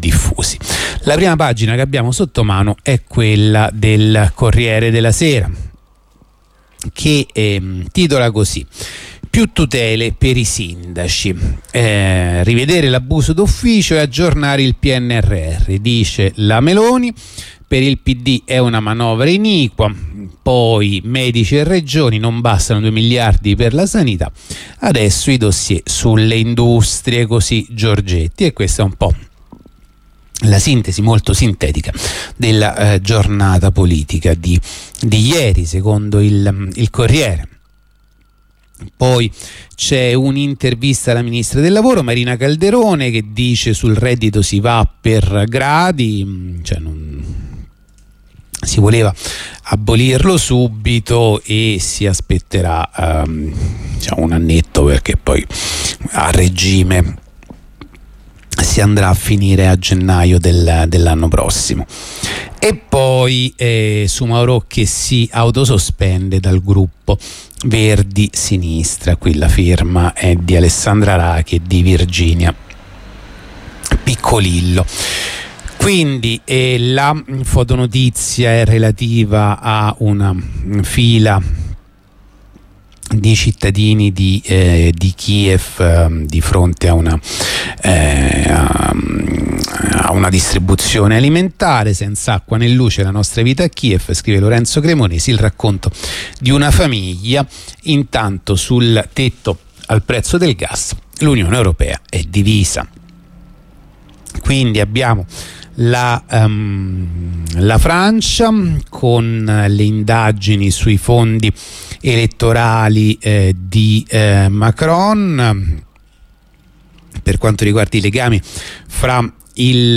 La rassegna stampa di radio onda rossa andata in onda venerdì 25 novembre 2022